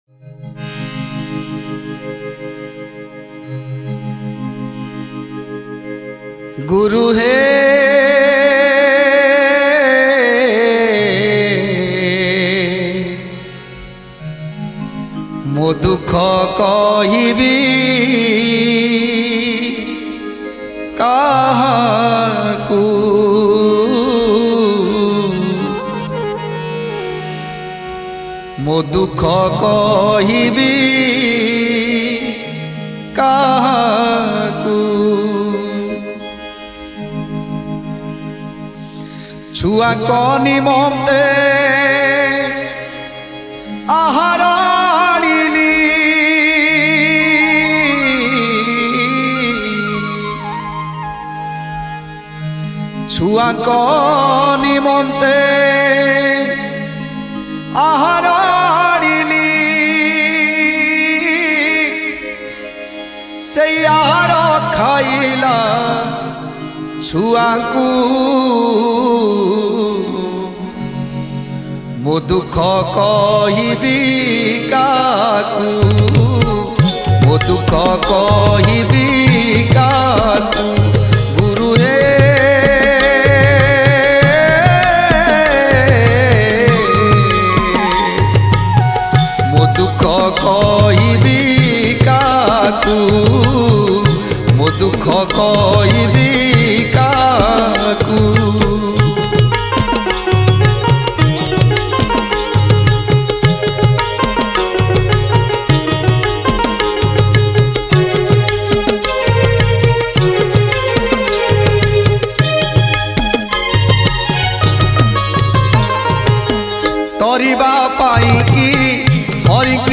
Sri Sri Jagannath Stuti